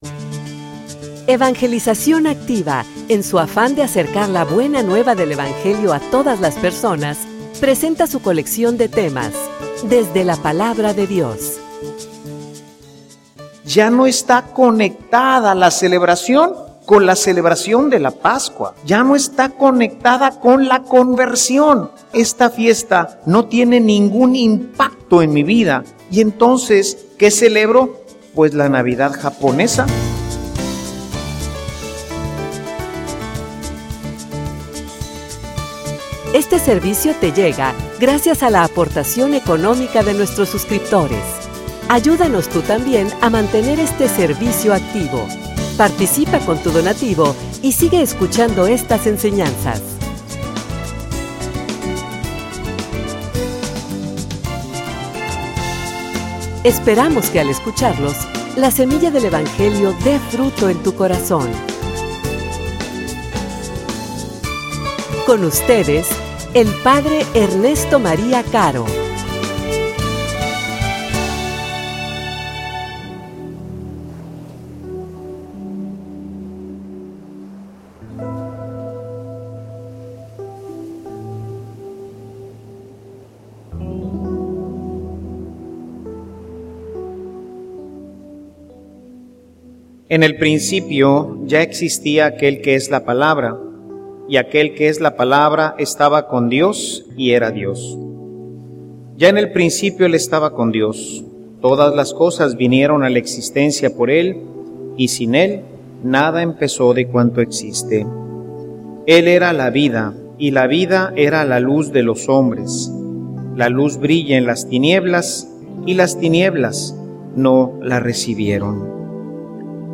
homilia_Y_tu_que_festejas.mp3